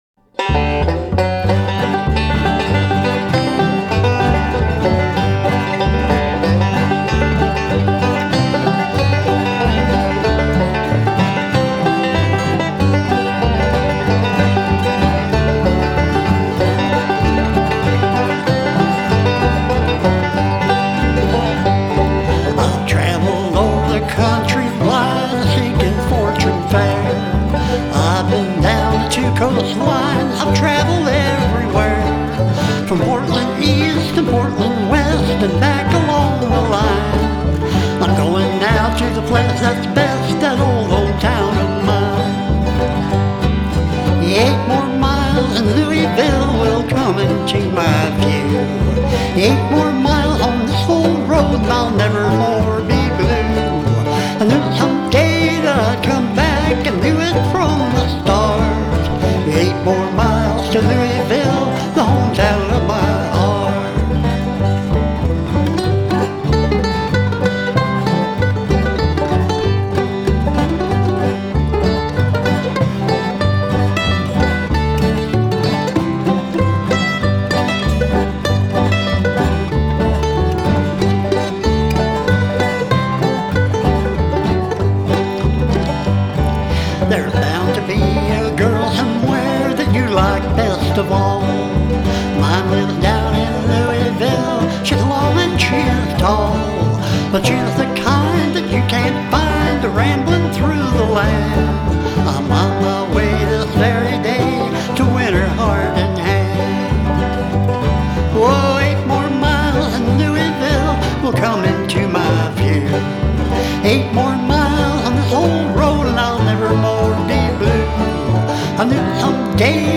Newest 100 Bluegrass (Scruggs) Songs banjo songs which Banjo Hangout members have uploaded to the website.